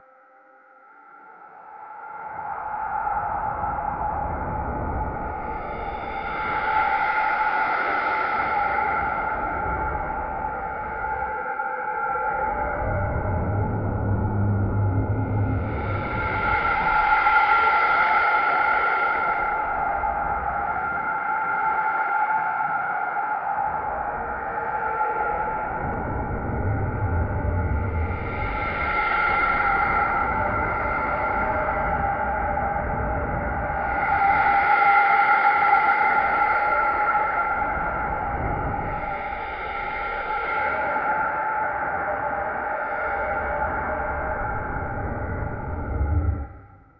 the-breath-of-a-living-u3ivdmcw.wav